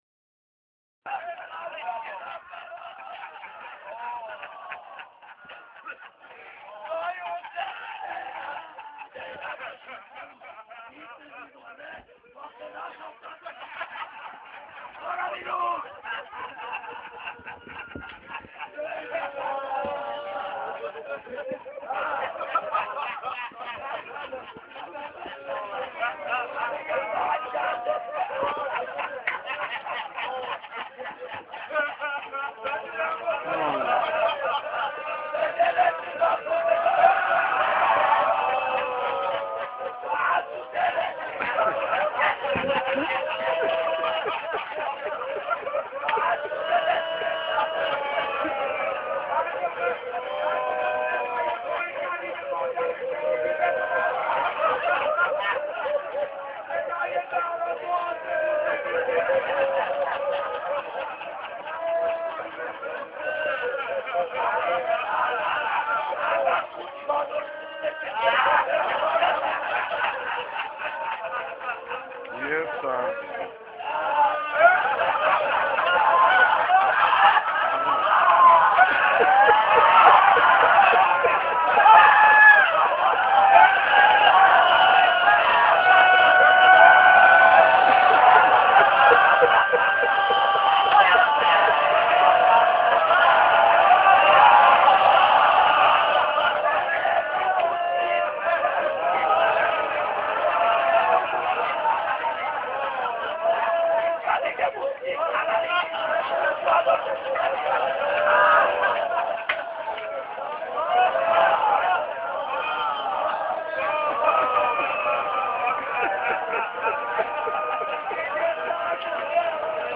سخنرانی حجت الاسلام و المسلمین شیخ حسین انصاریان در موضوع شخصیت امام حسین علیه السلام.